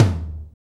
Index of /90_sSampleCDs/AMG - Now CD-ROM (Roland)/DRM_NOW! Drums/NOW_K.L.B. Kit 1
TOM KLB TO04.wav